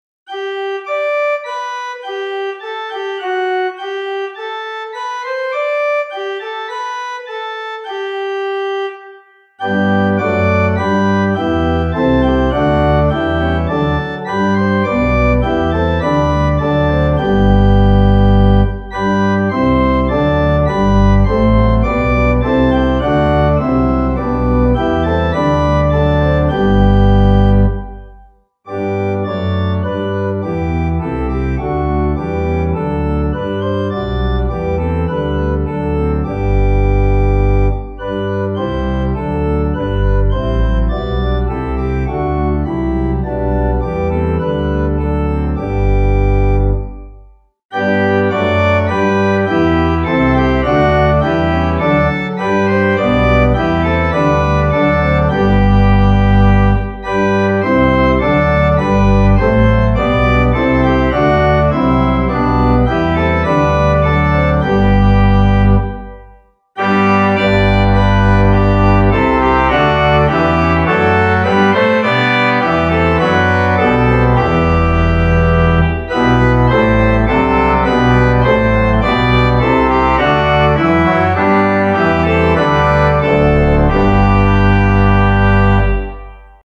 Organ: Little Waldingfield